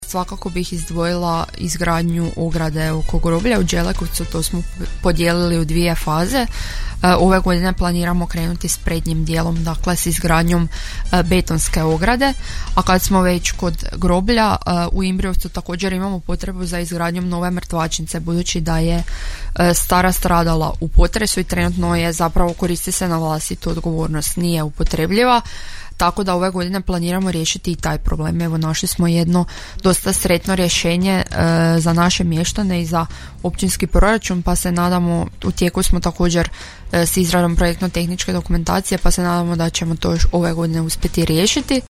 Načelnica općine Đelekovec Lara Samošćanec bila je gošća emisije „Susjedne općine” Podravskog radija